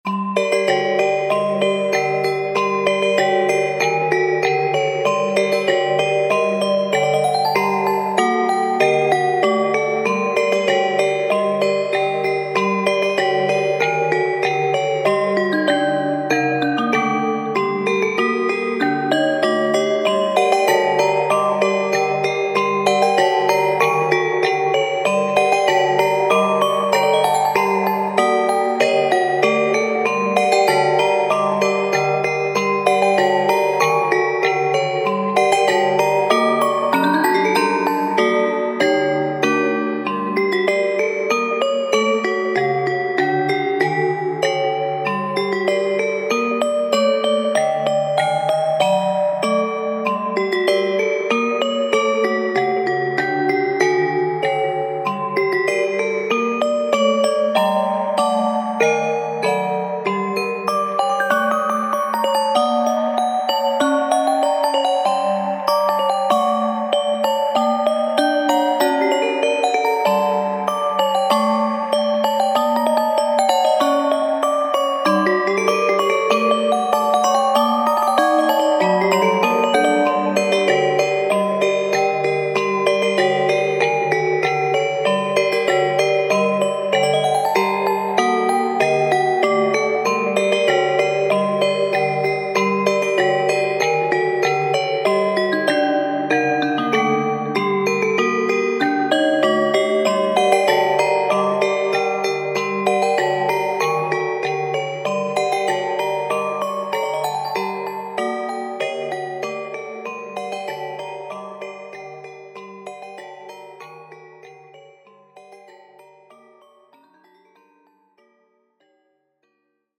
オルゴール コミカル ホラー/怖い 不思議/ミステリアス 不気味/奇妙 怪しい 暗い コメント